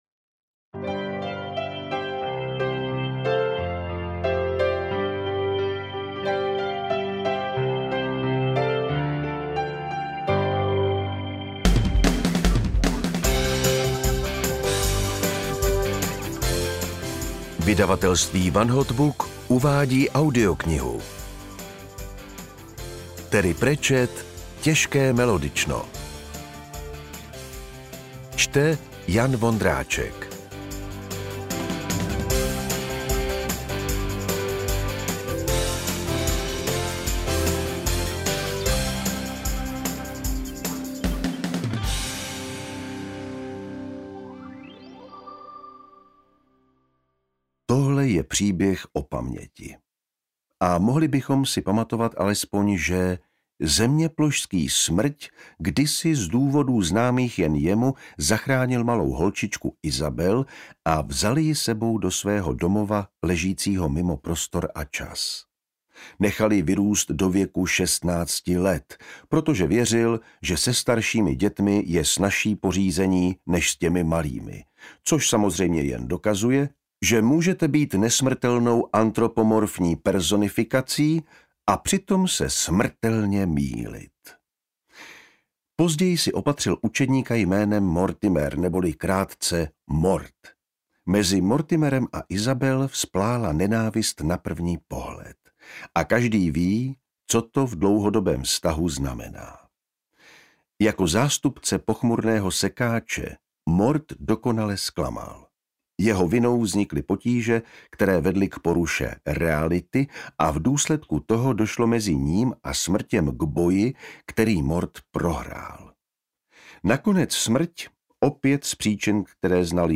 Audio knihaTěžké melodično
Ukázka z knihy
• InterpretJan Vondráček
tezke-melodicno-audiokniha